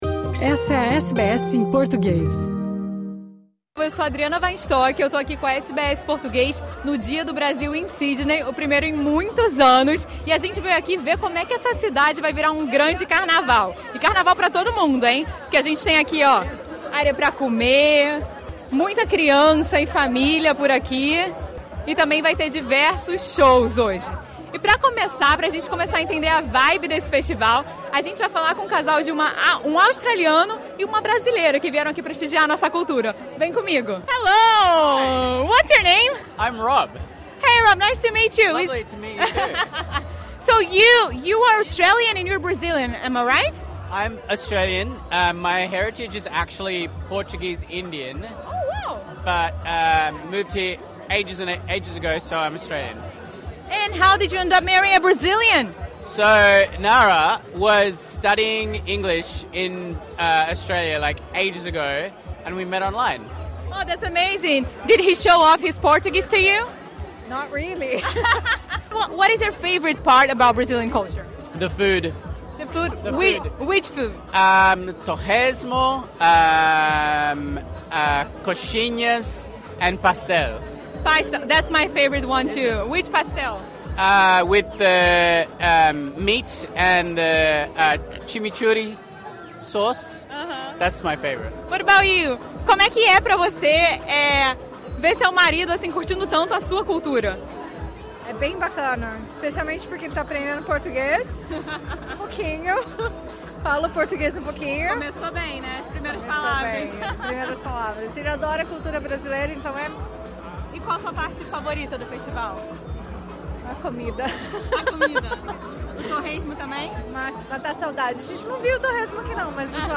O Dia do Brasil em Sydney aconteceu no último dia 15 de setembro no Fraser Park, em Marrickville. O evento ofereceu muita culinária brasileira, música, dança, moda e outros elementos da cultura brasileira, como o jiu-jitsu. A SBS em português esteve lá para conferir.